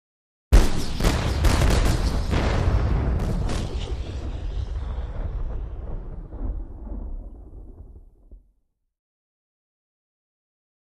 Large Multiple Cannon Fire; Six Or Seven Cannon Being Fired Together. Shots Are Offset Slightly To Give Rapid Fire Effect. Huge, Heavy Low End Blasts With Long Echo At Medium Close Perspective.